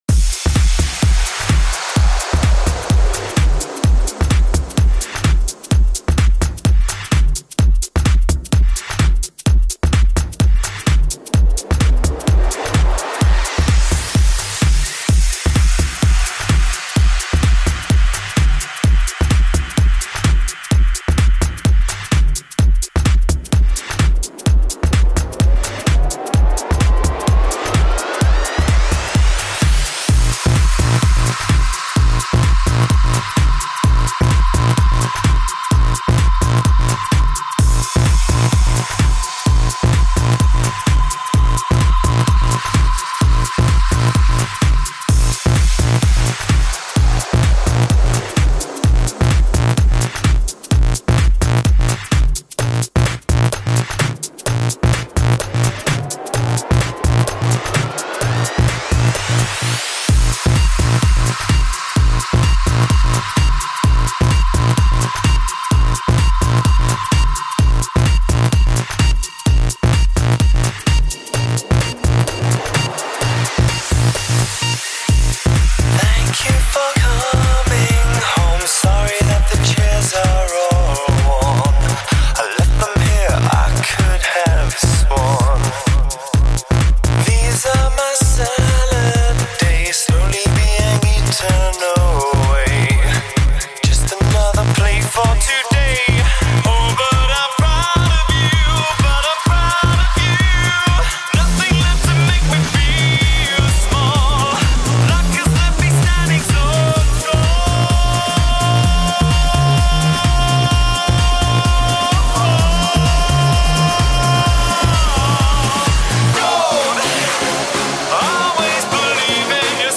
The first of a series of mixes created during the lockdown.